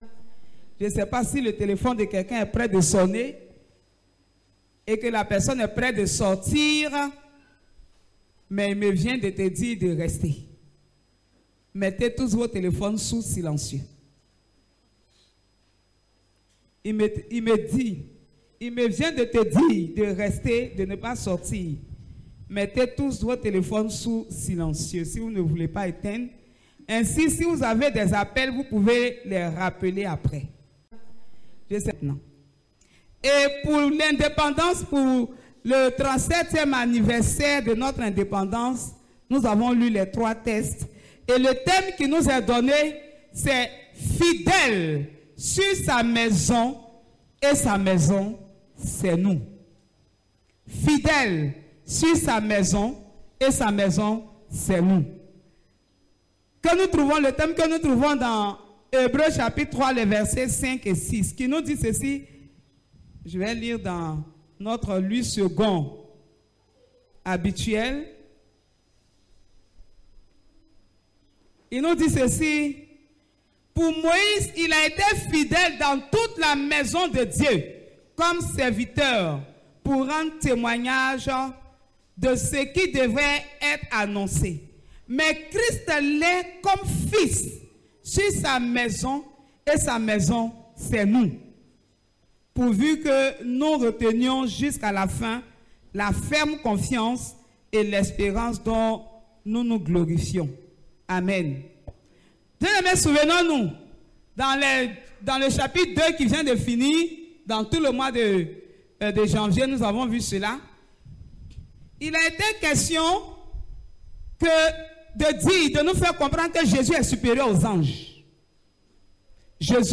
c’est nous Prédicateur